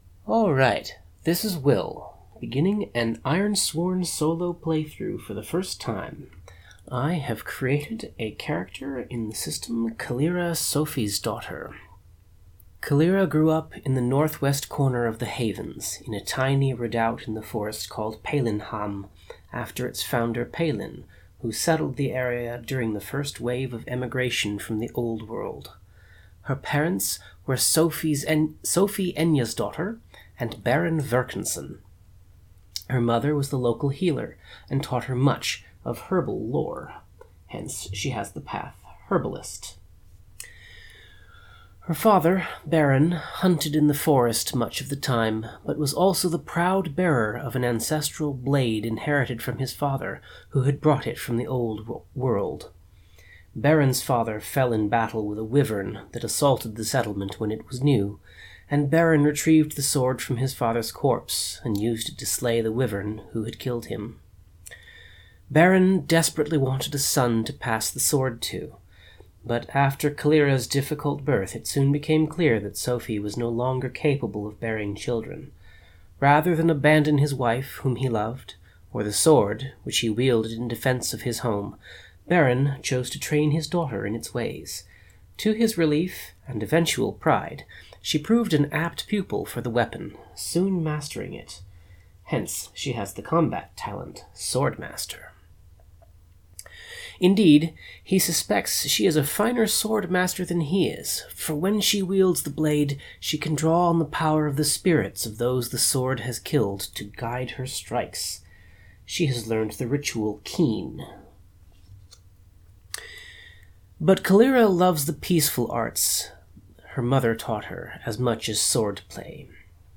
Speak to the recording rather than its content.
Note: This is my very first session of Ironsworn. It has been lightly edited to remove extended bits of silence, paper-shuffling, and so on.